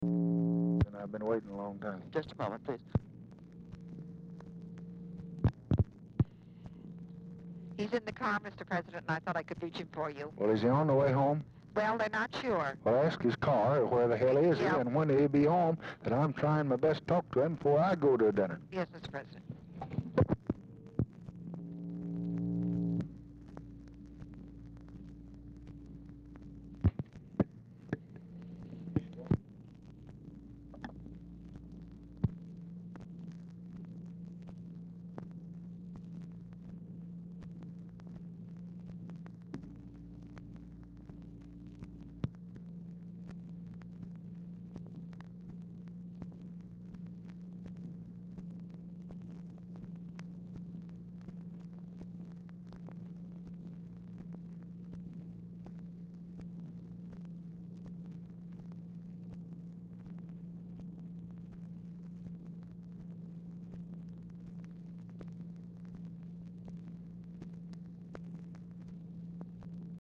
Telephone conversation # 3902, sound recording, LBJ and TELEPHONE OPERATOR, 6/25/1964, time unknown | Discover LBJ
Format Dictation belt
Specific Item Type Telephone conversation